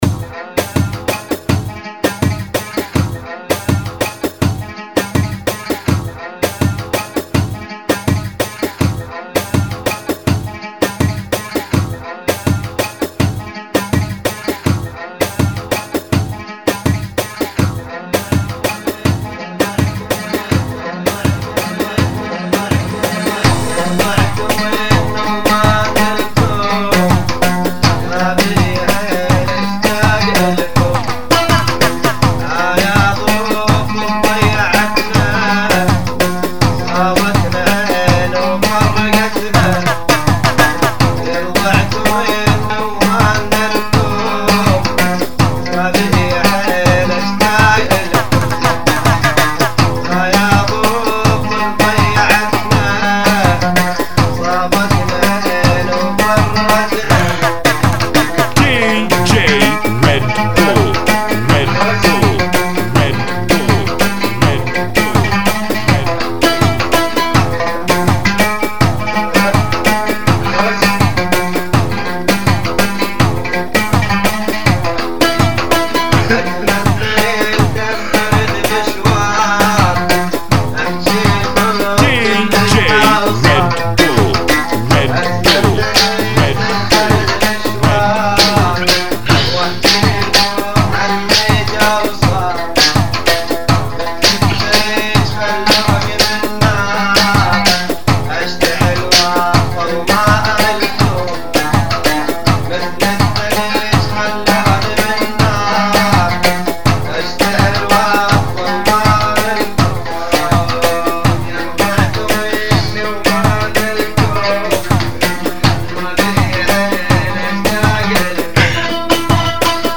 82 bpm